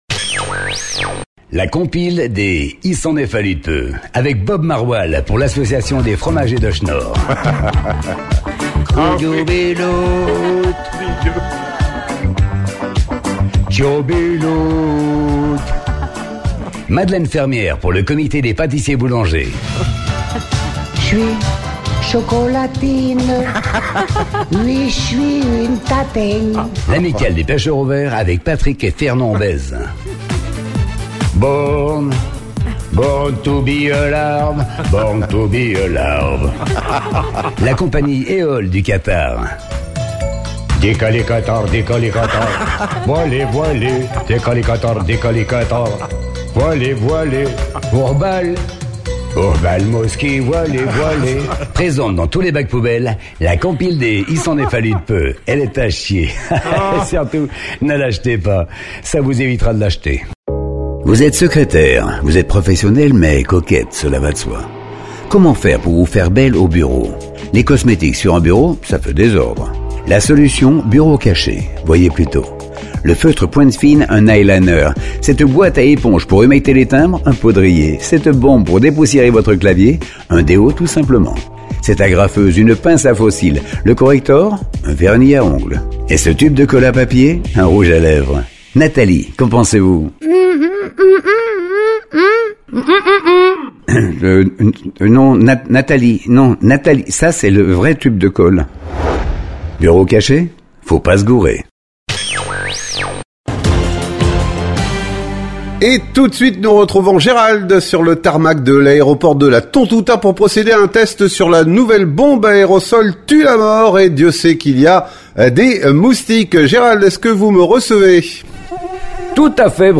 Le concept est simple : un studio, une idée loufoque, des voix et c'est parti pour des fausses pubs hilarantes sur Radio Rythme Bleu !